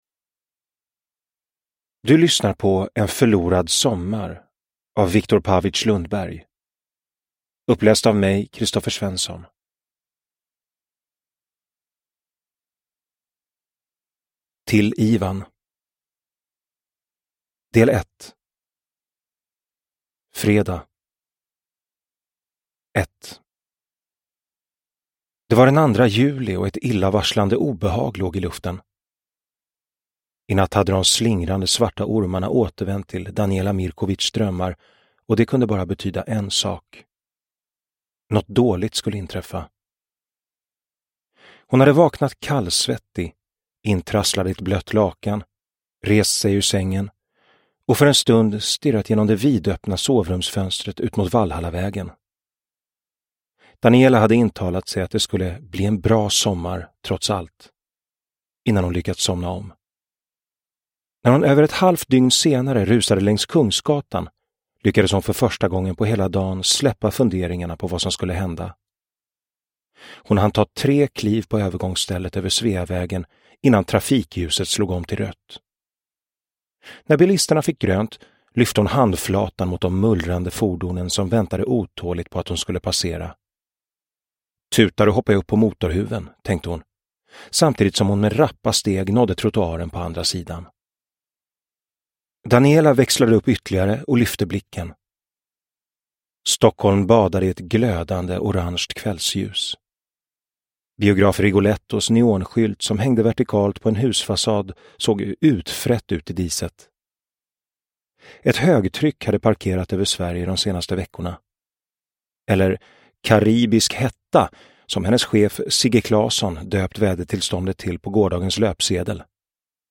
En förlorad sommar – Ljudbok – Laddas ner